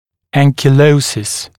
[ˌæŋkɪˈləusɪs][ˌэнкиˈлоусис]анкилоз, заместительная резорбция